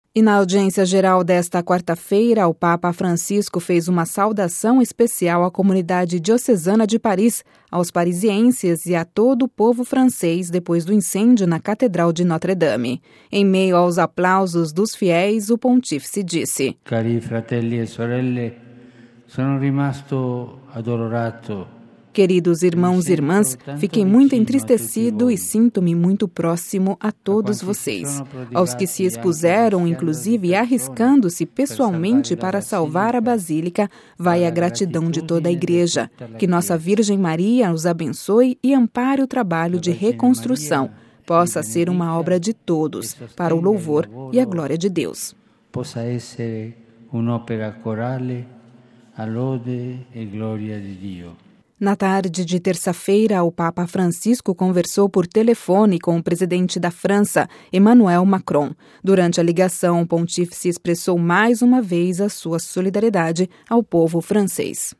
A multidão de fiéis na Praça São Pedro fez uma salva de palmas quando o Pontífice saudou os peregrinos de língua francesa, ao recordar o incêndio que devastou a Catedral de Notre Dame.
Em meio aos aplausos dos fiéis, o Pontífice disse:
Ouça a reportagem com a voz do Papa Francisco